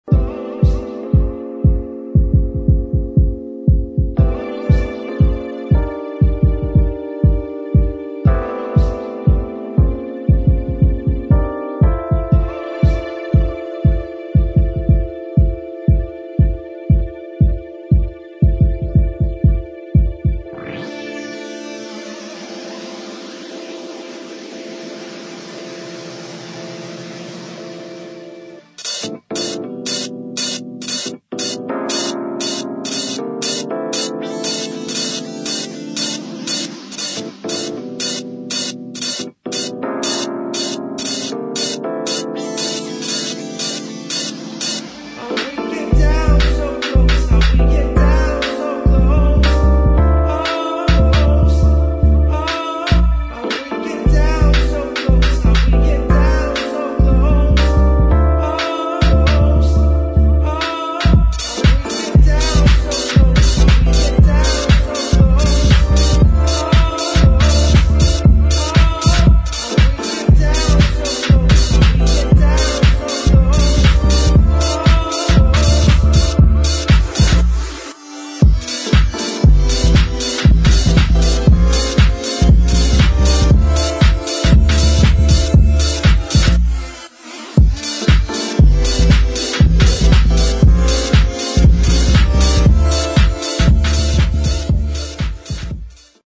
[ HOUSE / AFRO / SOUL ]